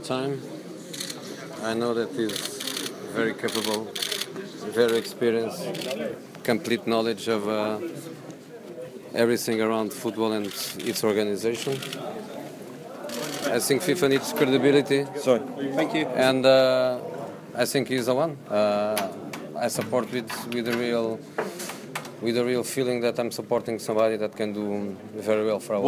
Jose Mourinho tells me why he's backing Infantino for FIFA president - security intervened at Wembley as Man United questioned could be asked and he was whisked away.